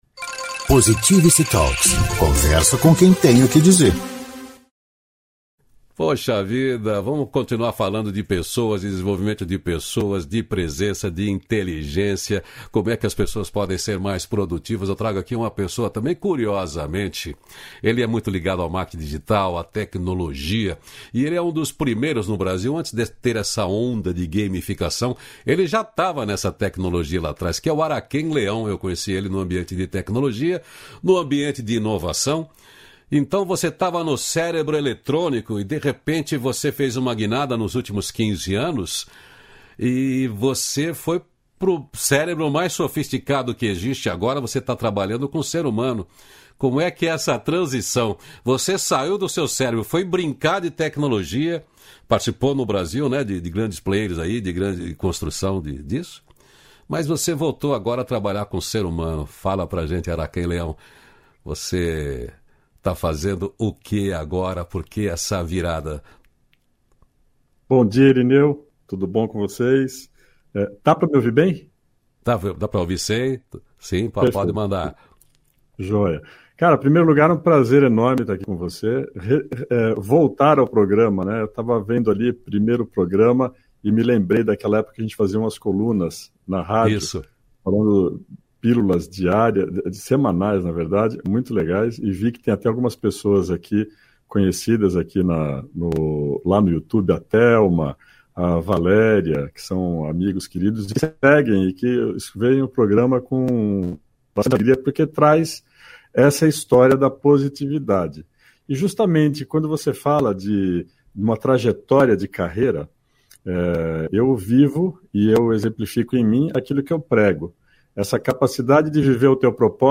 294-feliz-dia-novo-entrevista.mp3